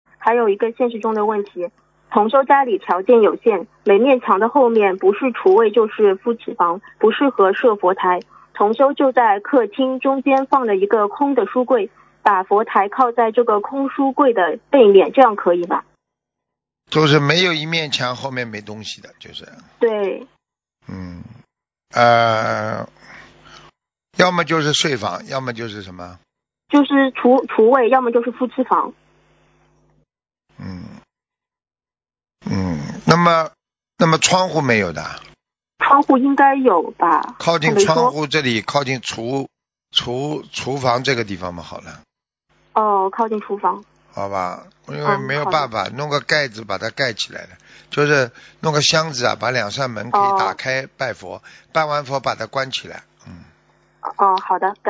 目录：☞ 2019年10月_剪辑电台节目录音_集锦